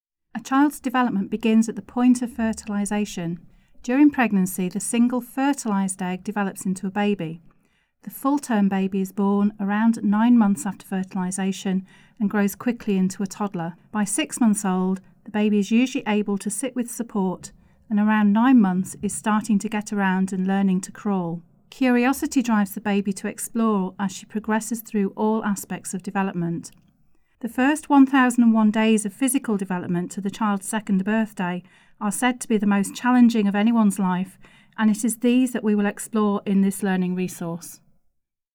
Narration audio (OGG)